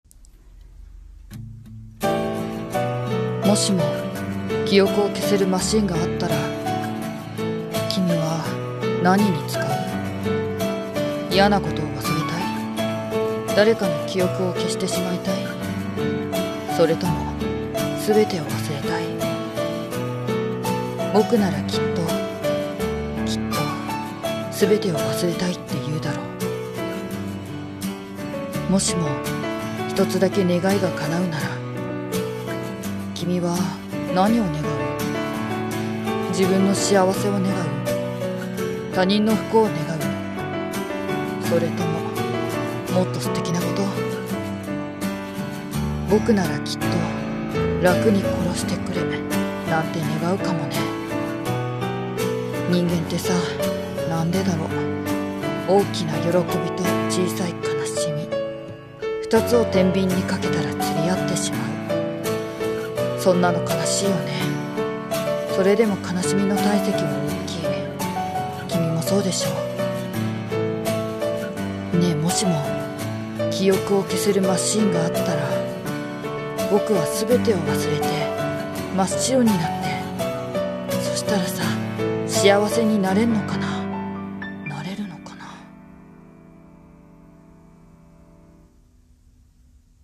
】【声劇台本】 ねぇ、もしも 声劇 nanaRepeat